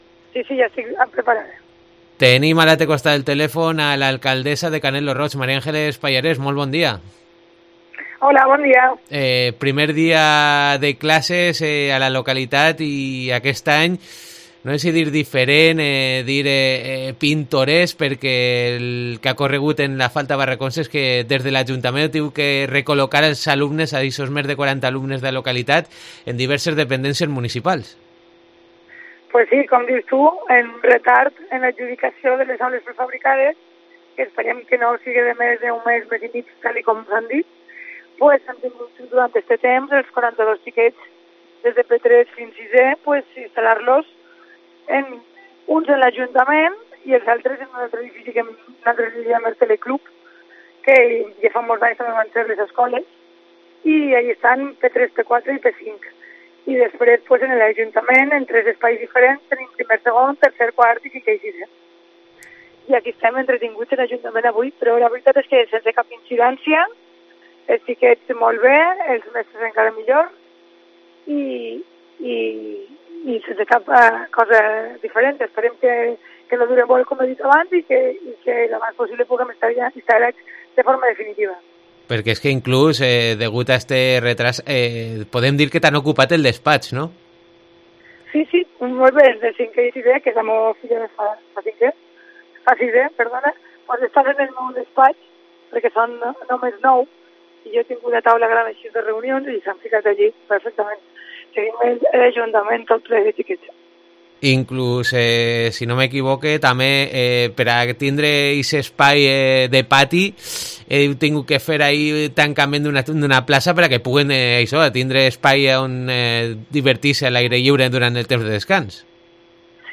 Entrevista a María Ángeles Pallarés, alcaldesa de Canet lo Roig